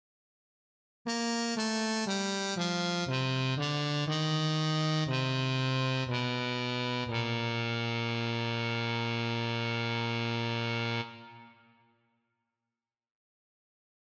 Key written in: B♭ Major
Type: Barbershop
Each recording below is single part only.